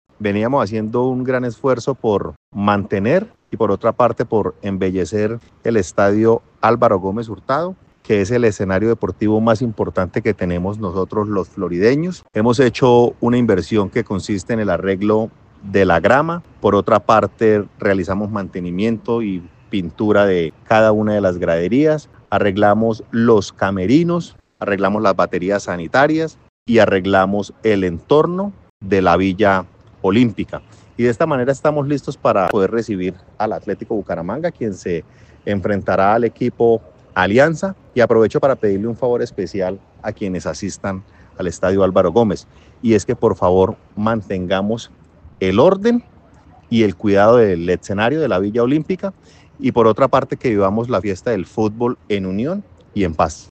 José Fernando Sánchez, Alcalde de Floridablanca